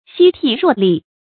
夕惕若厲 注音： ㄒㄧ ㄊㄧˋ ㄖㄨㄛˋ ㄌㄧˋ 讀音讀法： 意思解釋： 若：如；厲：危。朝夕戒懼，如臨危境，不敢稍懈。